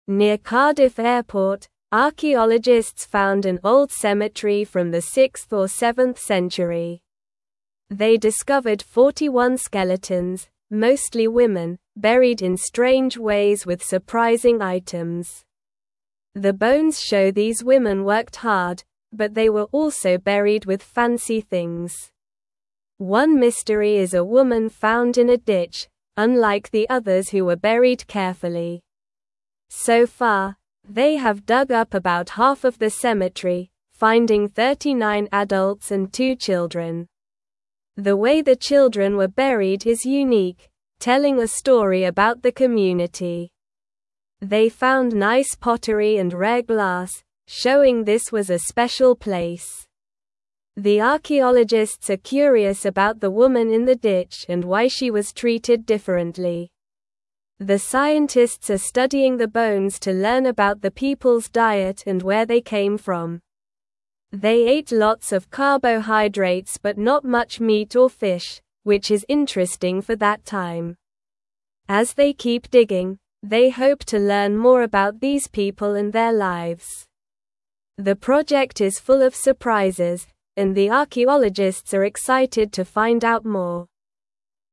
Slow
English-Newsroom-Lower-Intermediate-SLOW-Reading-Old-Cemetery-Found-Near-Cardiff-Airport.mp3